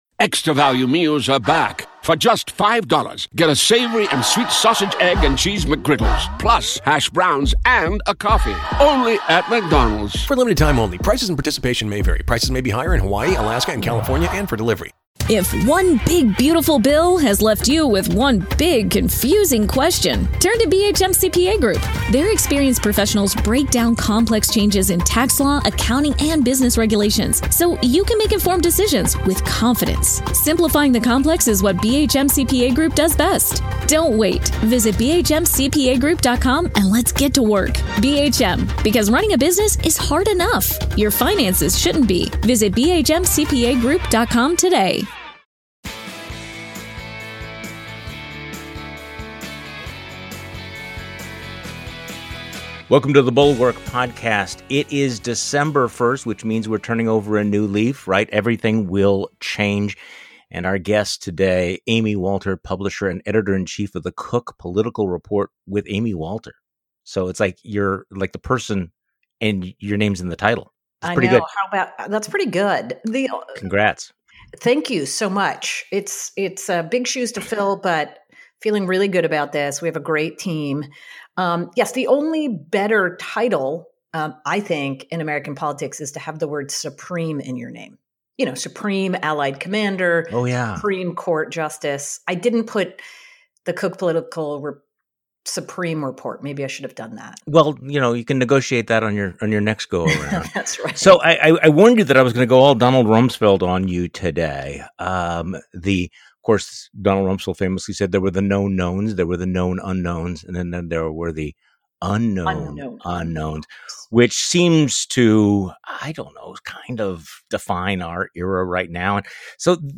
Just as we thought we were turning the corner on Covid, a new variant is bringing travel restrictions and heightening anxiety about the future. Is Biden in a no-win situation? The Cook Political Report's Amy Walter joins Charlie Sykes on today's podcast.
Special Guest: Amy Walter.